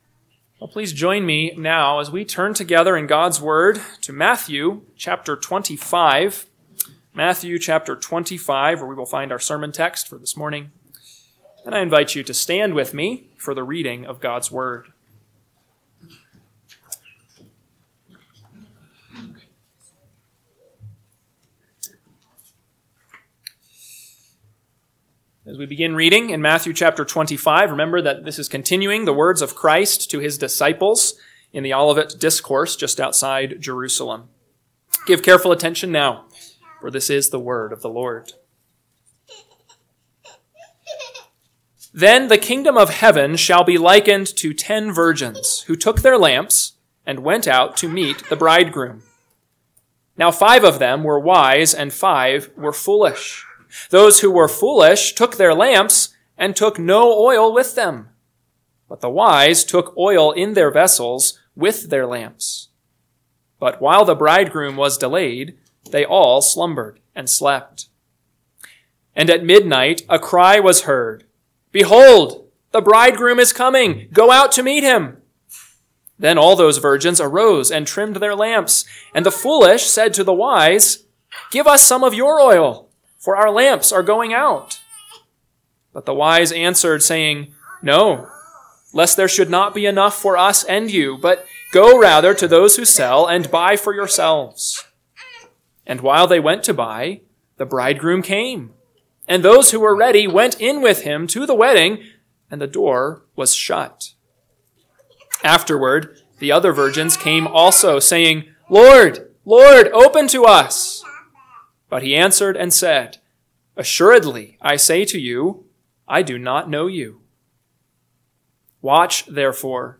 AM Sermon – 1/19/2025 – Matthew 25:1-13 – Northwoods Sermons